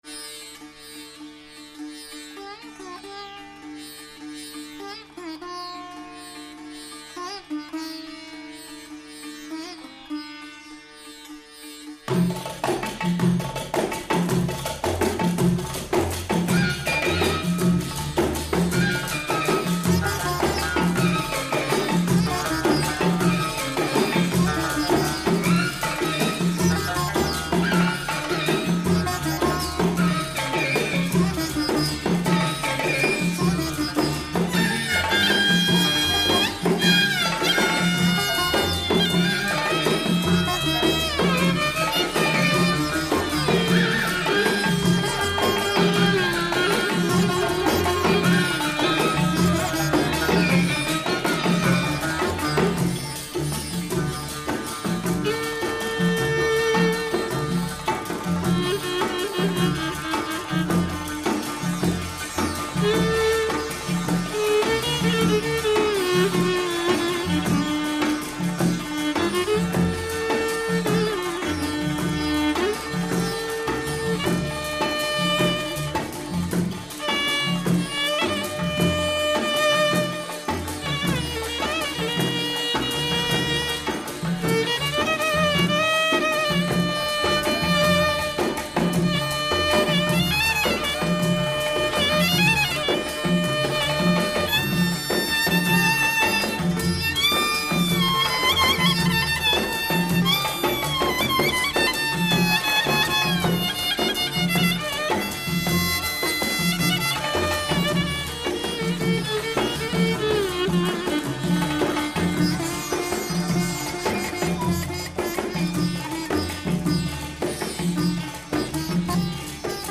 sitar